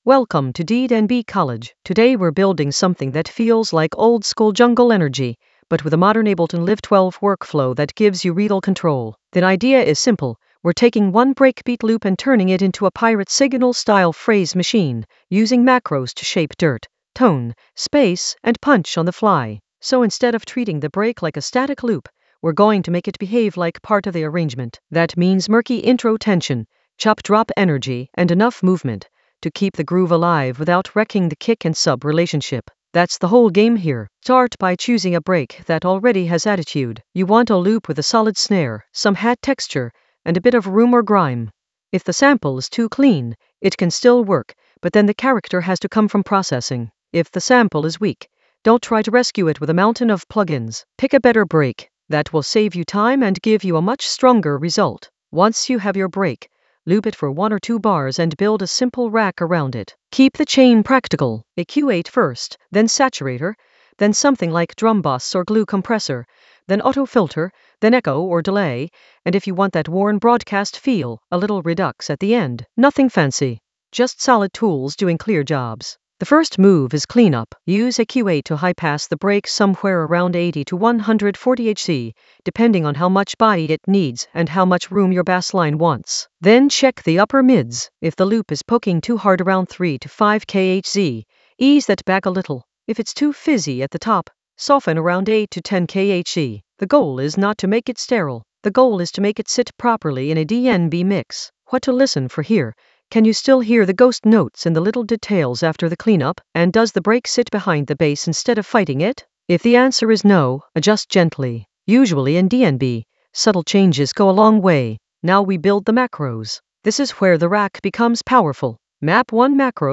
An AI-generated beginner Ableton lesson focused on Pirate Signal Ableton Live 12 an oldskool DnB breakbeat blueprint using macro controls creatively for jungle oldskool DnB vibes in the Workflow area of drum and bass production.
Narrated lesson audio
The voice track includes the tutorial plus extra teacher commentary.